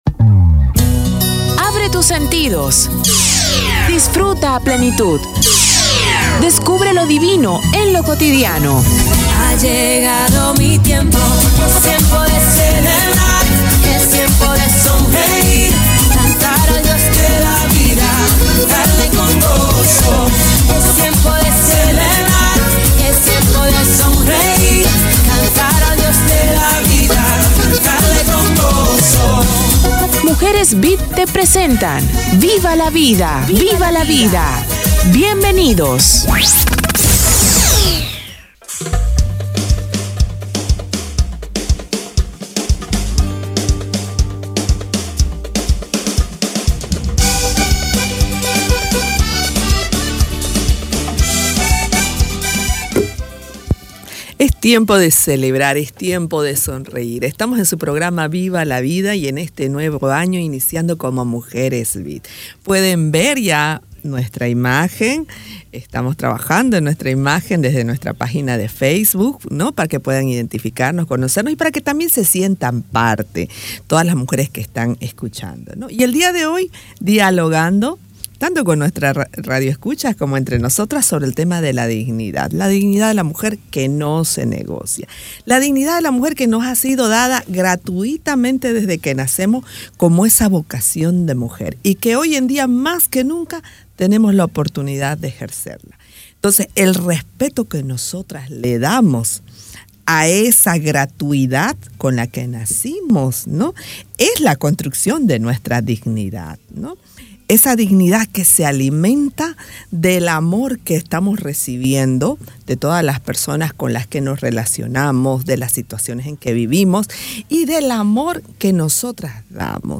Diálogo sobre la dignidad de la mujer